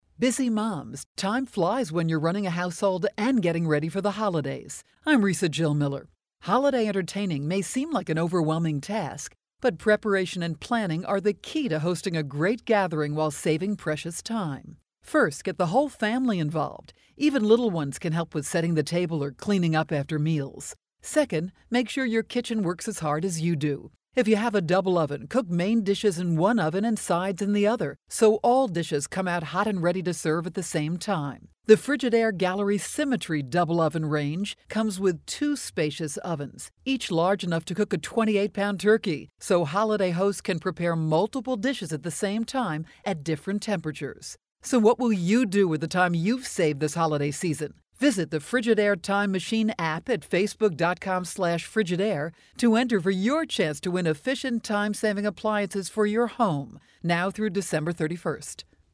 December 18, 2012Posted in: Audio News Release